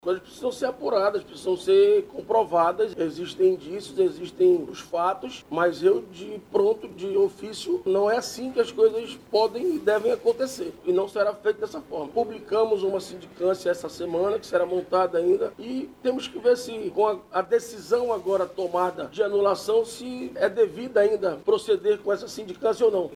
A anulação do concurso público realizado em 2024, foi feita na tarde desta sexta-feira 14/03, pelo presidente da Câmara Municipal de Manaus – CMM, o vereador Davi Reis, durante uma entrevista coletiva.
Questionado sobre possível afastamento de um profissional da CMM, por suspeita de favorecimento, o presidente da Casa Legislativa, Davi Reis, disse que o caso será apurado.